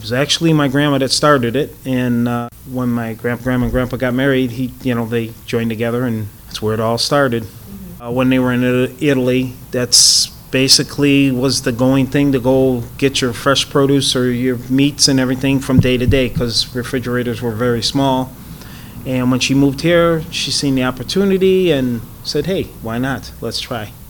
| Source: Cleveland Regional Oral History Collection